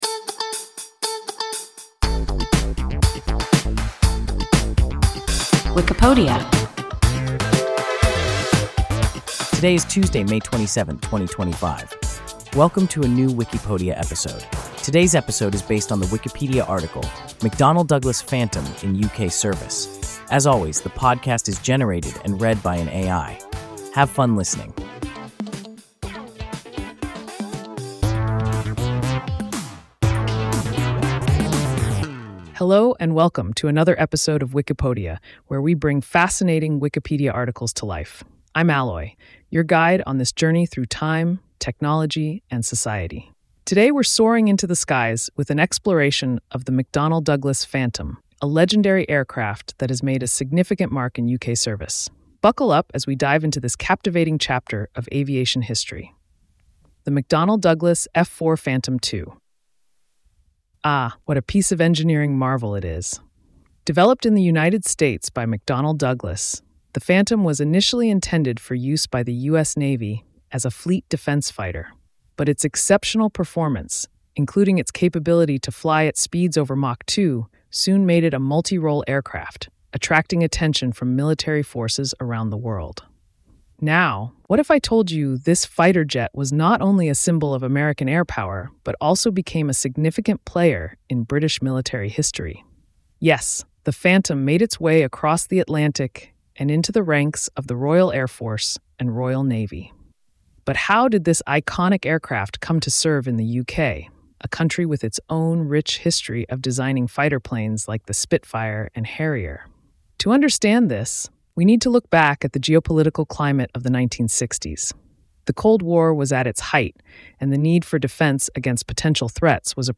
McDonnell Douglas Phantom in UK service – WIKIPODIA – ein KI Podcast